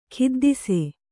♪ khiddise